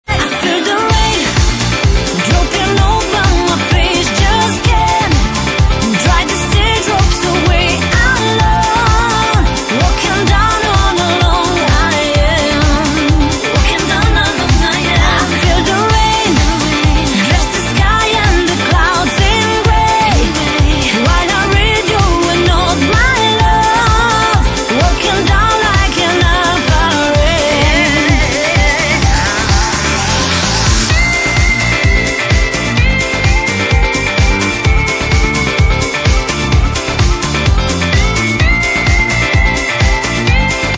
Электронная
Клубный микс